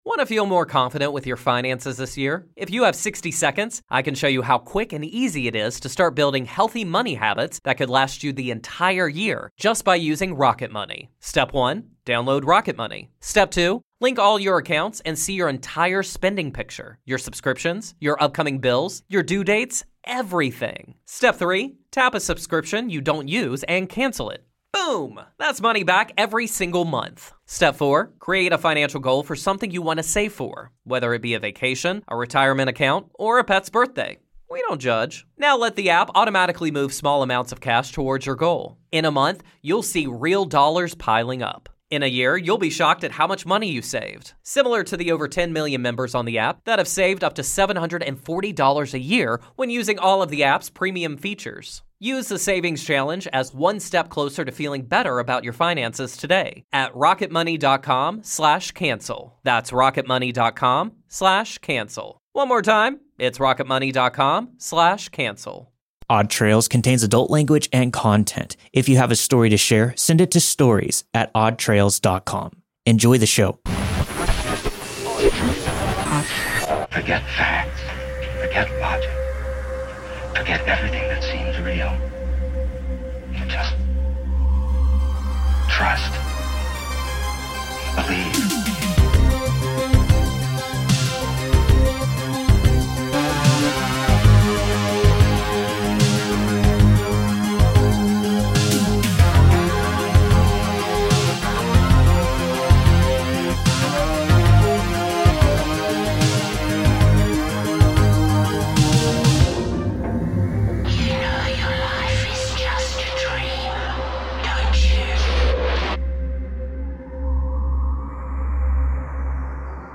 All stories were narrated and produced with the permission of their respective authors.